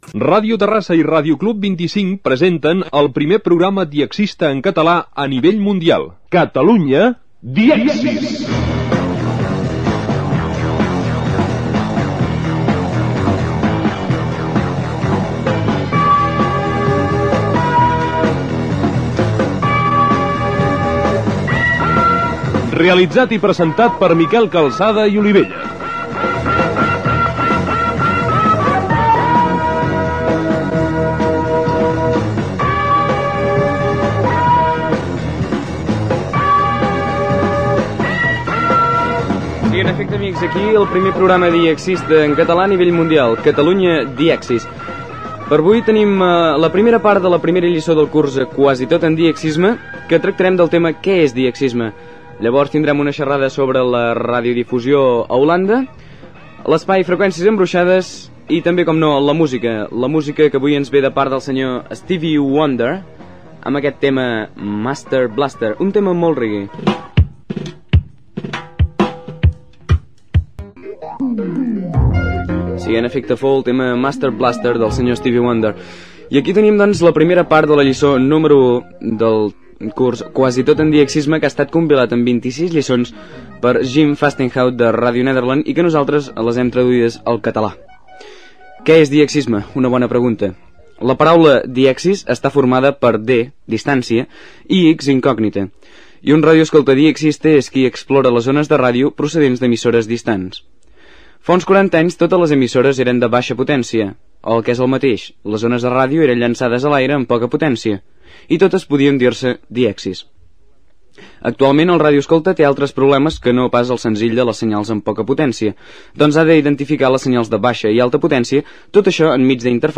Sumari. Tema musical.
Divulgació